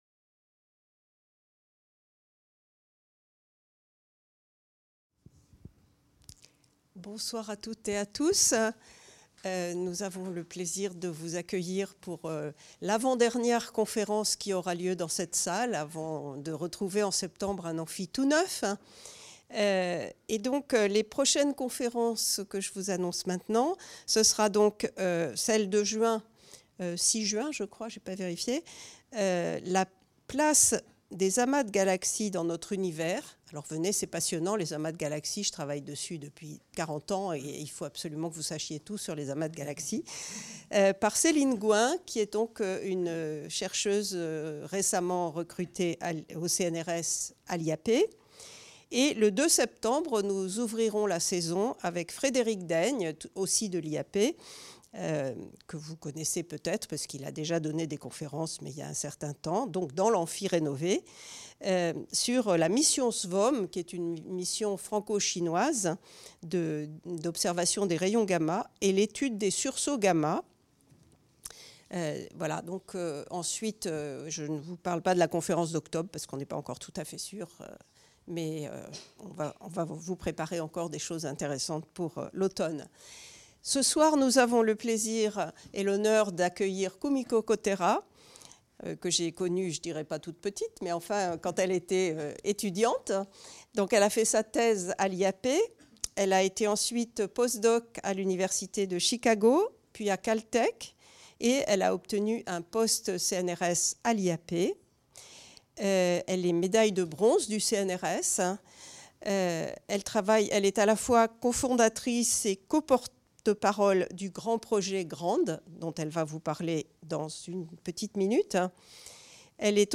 Véritables paparazzis du cosmos, les astronomes capturent aujourd'hui d'éphémères supernovae, pulsars, coalescences de trous noirs, sursauts gamma, etc. Sources d'une extraordinaire quantité d'énergie, ils libèrent de la lumière, mais aussi des rayons cosmiques, des neutrinos ou encore ces ondes gravitationnelles que nous détectons depuis peu. Dans cette conférence, nous partirons à l'exploration de ce nouveau champ d